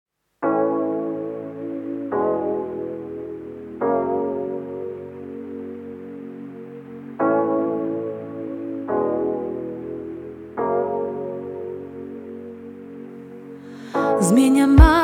Perkusja
Gitary
Instrumenty klawiszowe, bass, instr. perkusyjne, piano